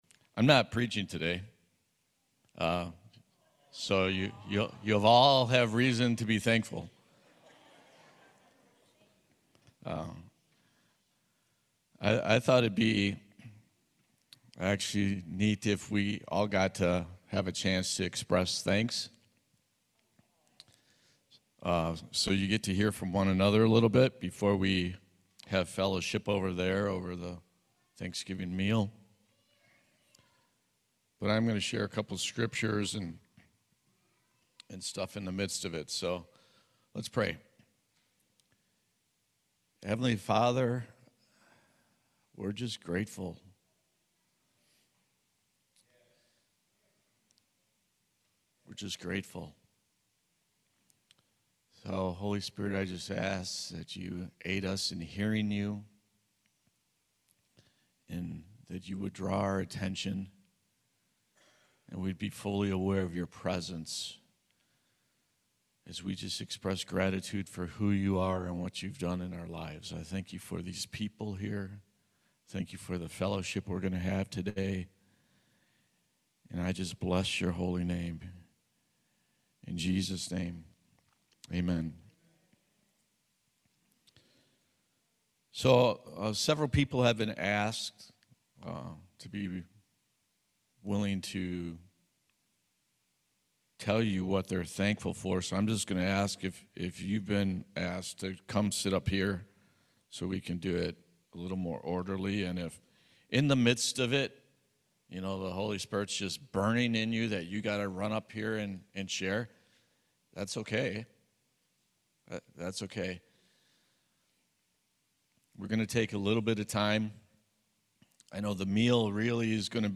Service Type: Main Service